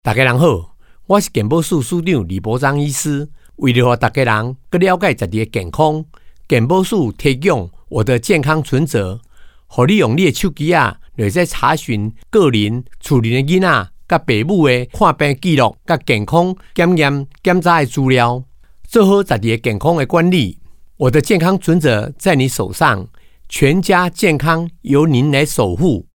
廣播
• 衛福部健保署-健康存摺-提醒篇-署長台語OS